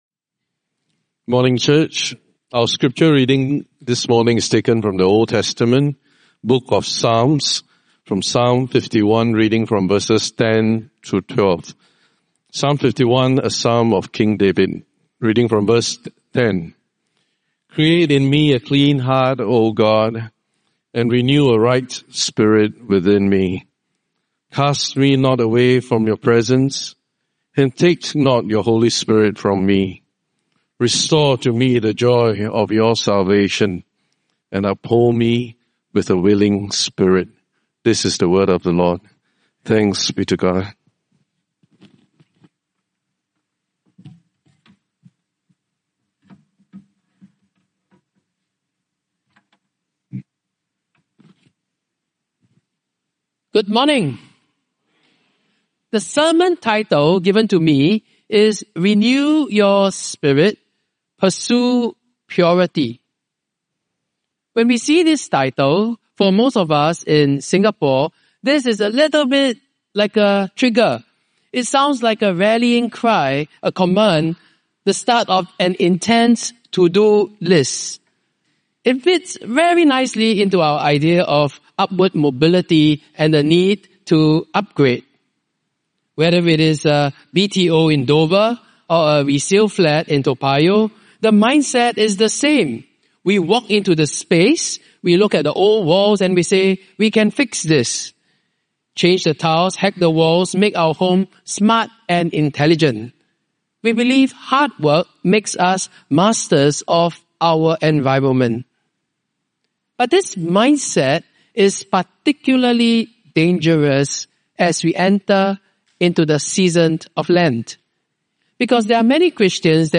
Sermon Archives - St.John's-St.Margaret's Church